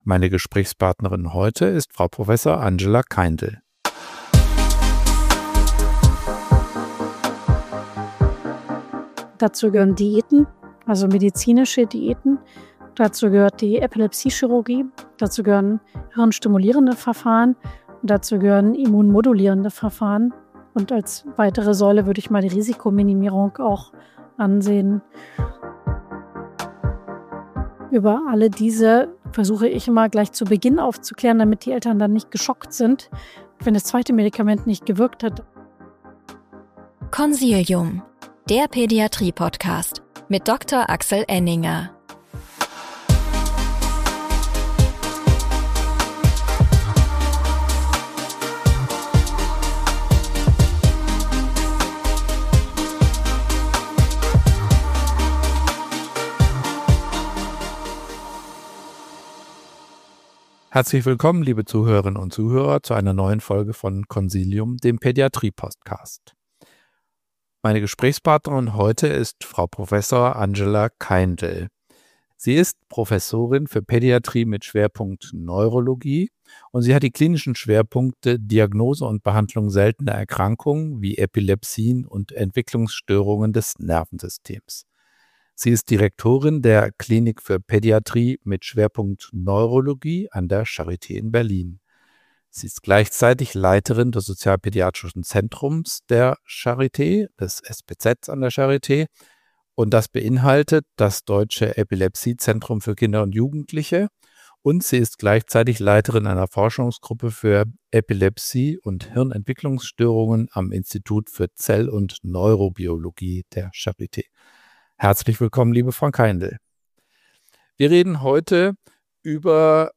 Ihre Stimme hat Nachdruck, wenn es um die Risiken geht, denn keine Epilepsie ist „benigne“. Zu einer guten Basisdiagnostik und korrekten Klassifikation gehört auch die Genetik.